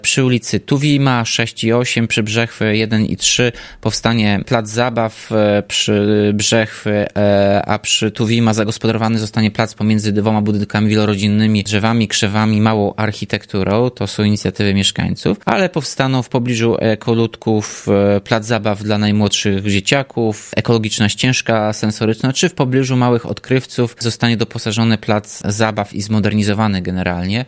-W sumie zrealizowanych zostanie 5 pomysłów, które zgłosili sami mieszkańcy – mówi Tomasz Andrukiewicz, prezydent Ełku.